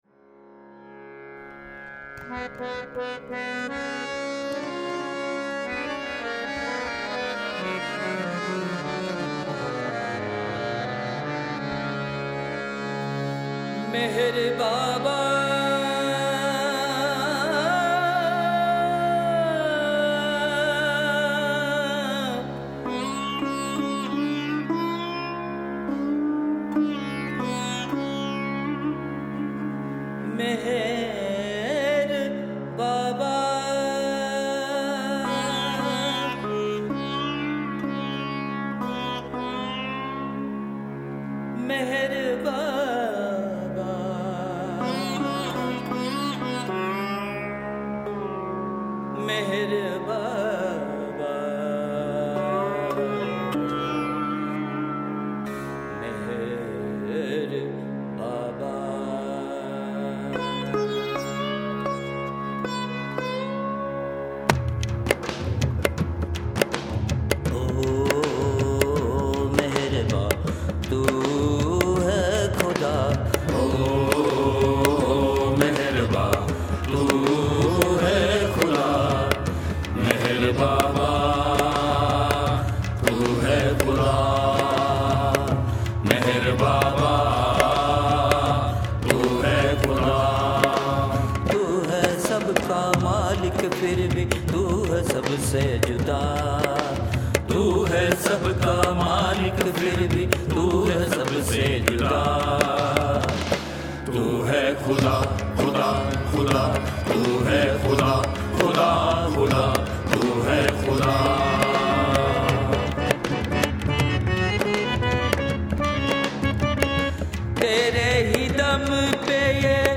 A main focus of our music is international collaboration.
Bringing together different musical cultures (Western, Latin, Persian and Indian) and fusing them into a new sacred music.